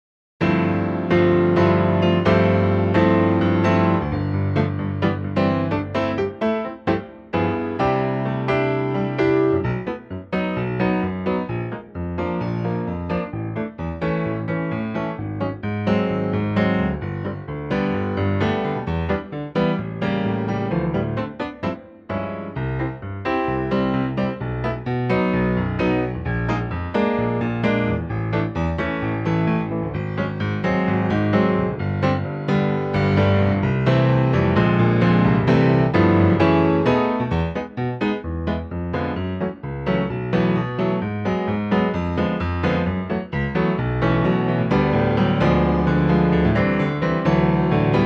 Unique Backing Tracks
key - C - vocal range - G to E (G optional top note)